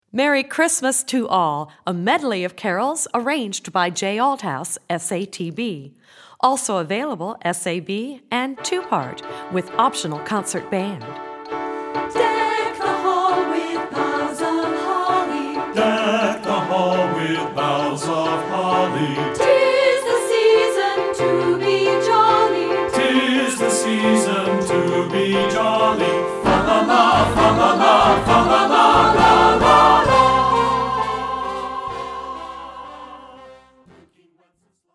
Instrumentation: Choral Pax
instructional, secular choral